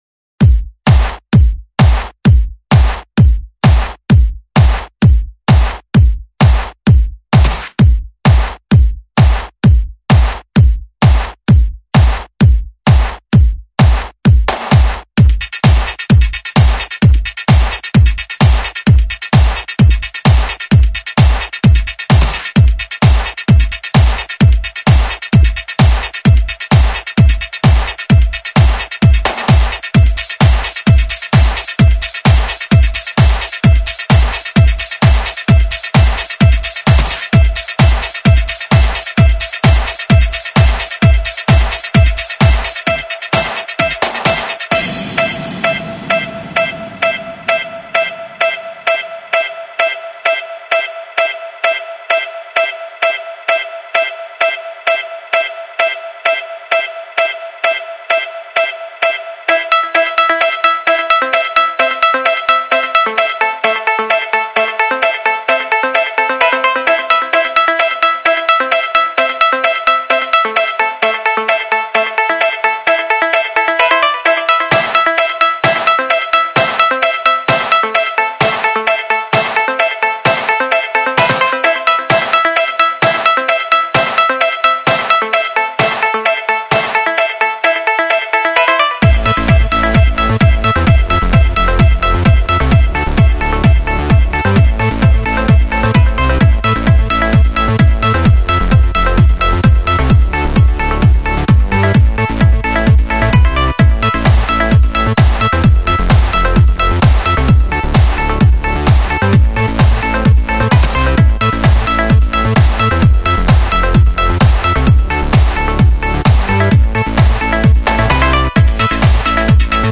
Жанр:Electro/House